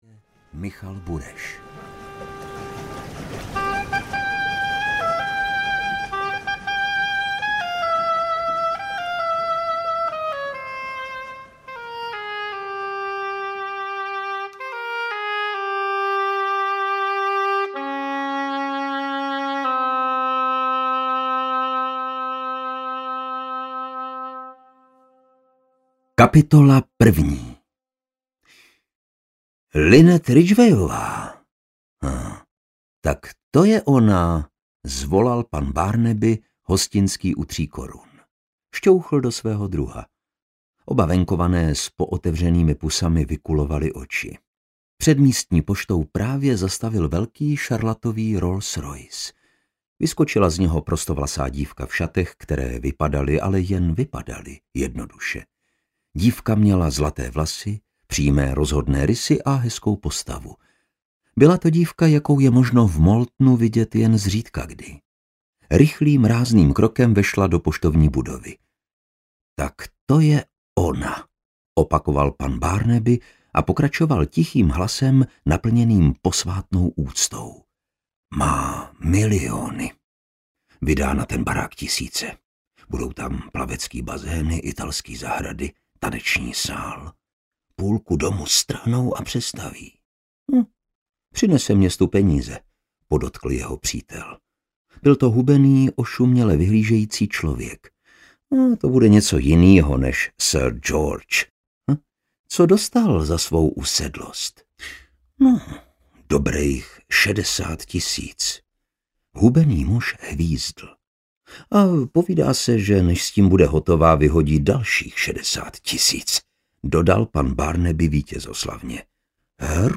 Smrt na Nilu audiokniha
Ukázka z knihy
smrt-na-nilu-audiokniha